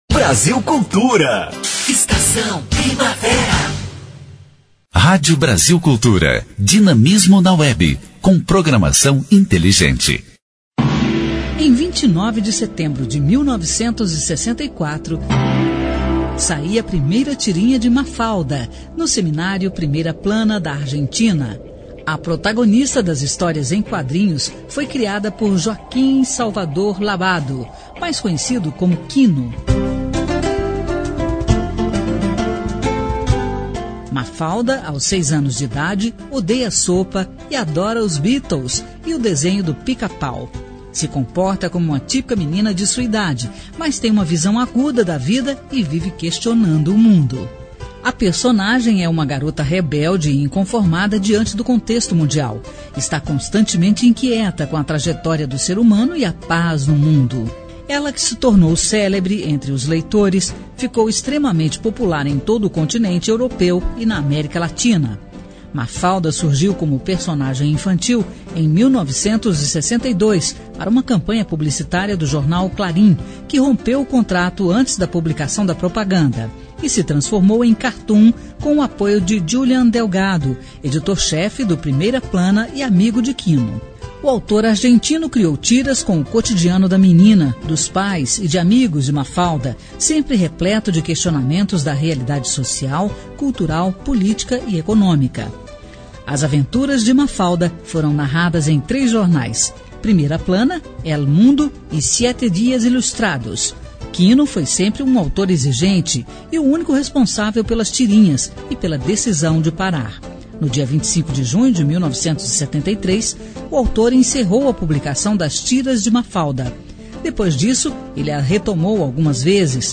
História Hoje: Programete sobre fatos históricos relacionados às datas do calendário.